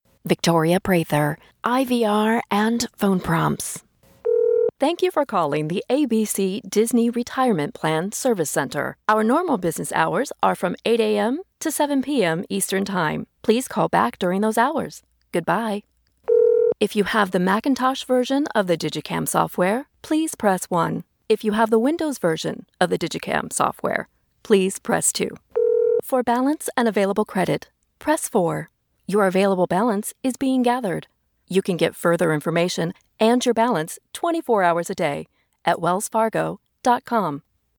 Animation:IVR/Phone Prompts: